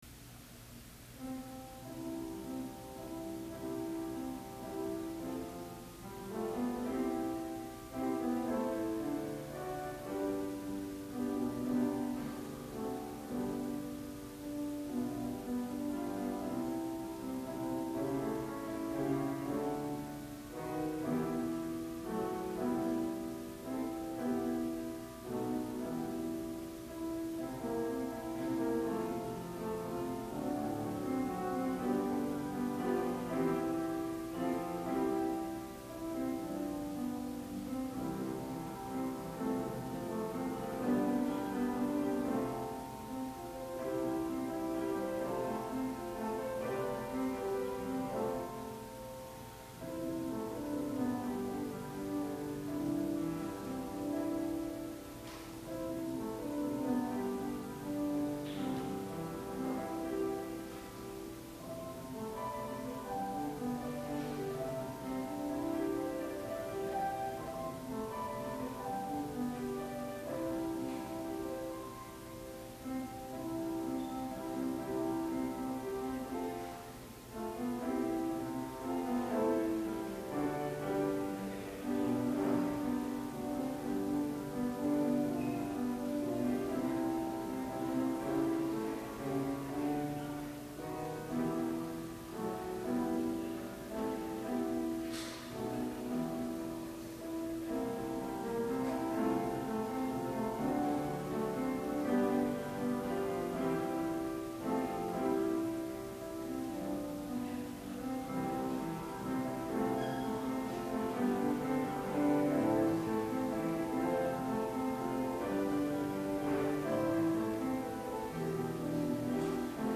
Week of July 15 July 18 Sermon Service